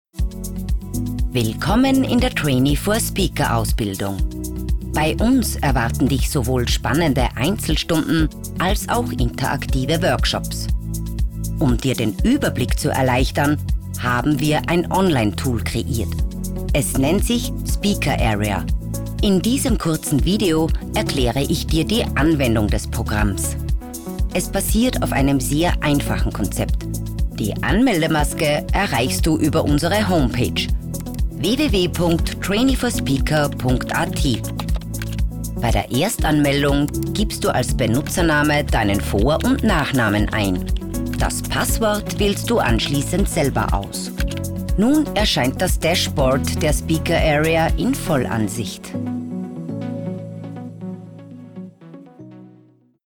Erklärvideo
Meine Frauenstimme hat Seltenheits- bzw. Wiedererkennungswert, da ich eine tiefe, markante und seriöse Klangfarbe habe.